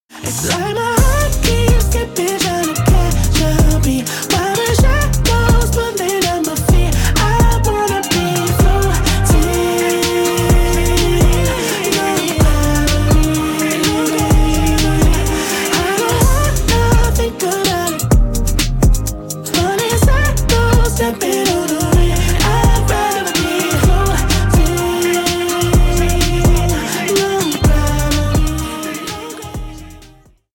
Exciting new voice with a soulful infectious groove